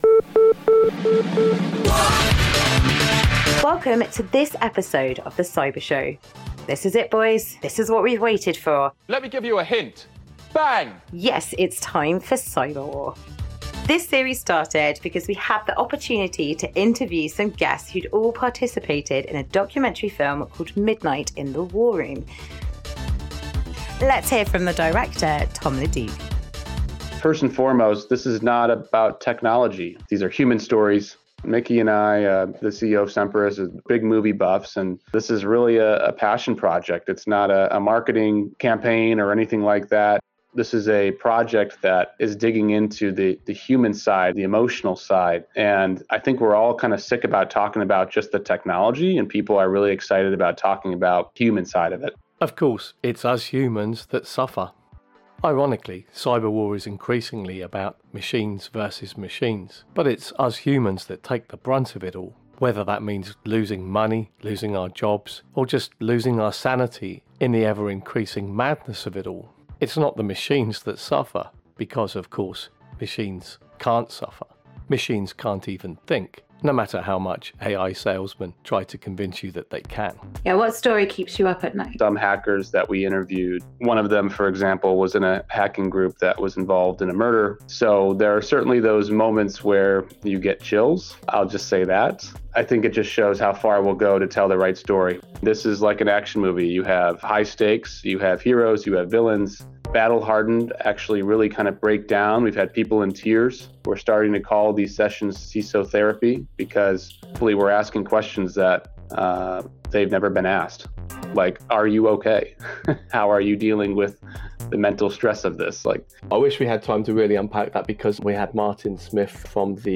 Categories: Audio Only In The Chair Interview Free Open Source Software Health and Technology Privacy, Dignity, Personal Data Distributed and Federated Systems Science, Research, Research Methods